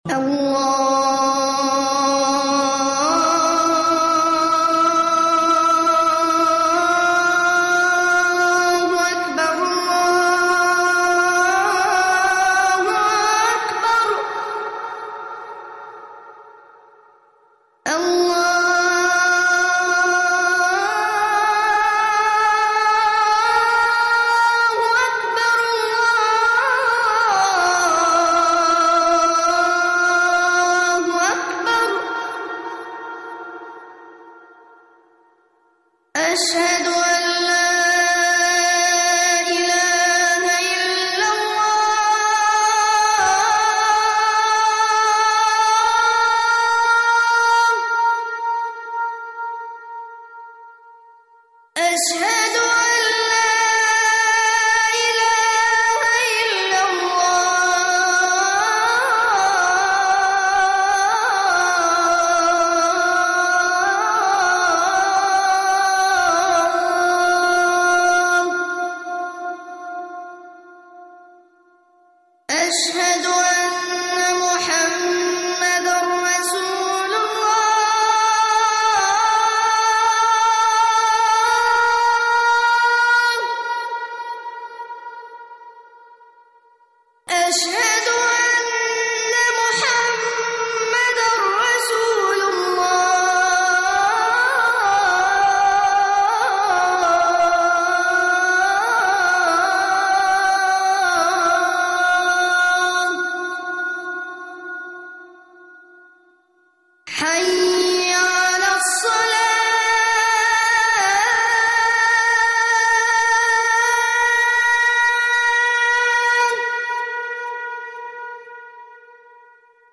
المكتبة الصوتية روائع الآذان المادة آذان
athan12.mp3